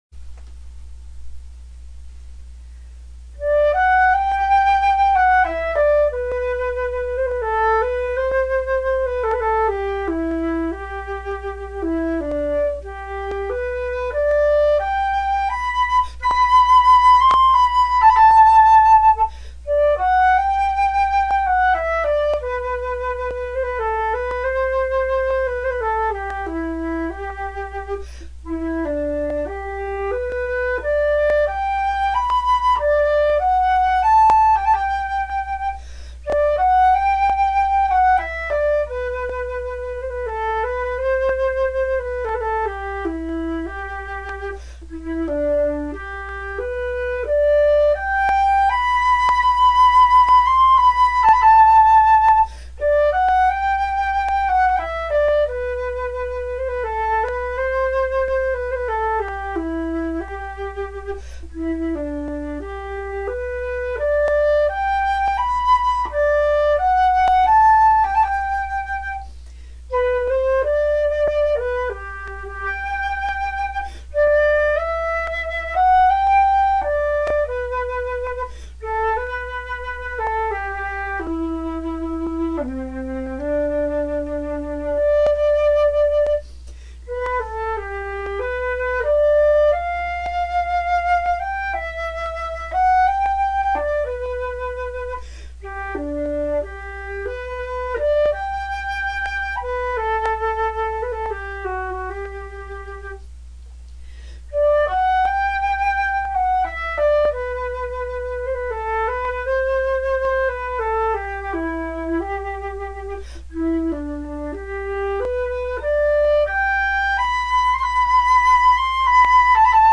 "C" Flute